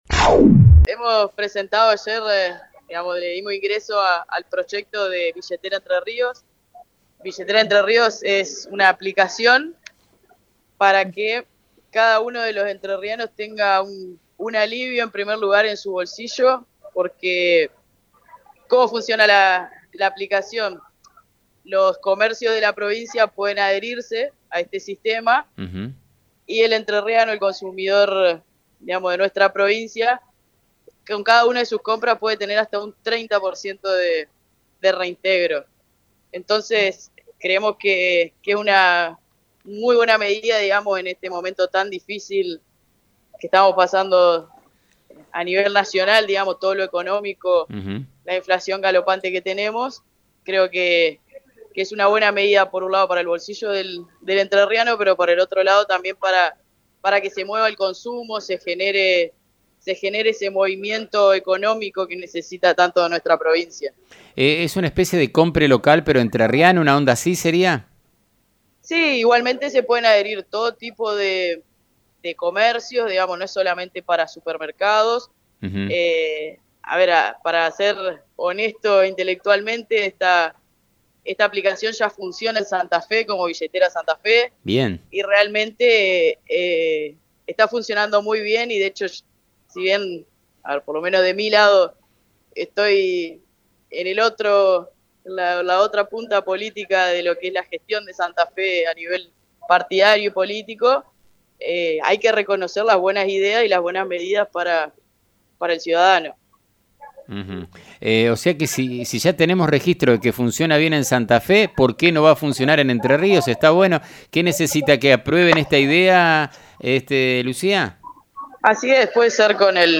En diálogo con FM 90.3 la diputada declaró: “para ser honestos intelectualmente, esta aplicación ya funciona como “Billetera Santa Fe” y realmente está funcionando muy bien y, si bien por lo menos de mi lado, estoy en la otra punta política de lo que es la gestión de Santa Fe a nivel partidario y político, hay que reconocer las buenas ideas y las buenas medidas para el ciudadano”.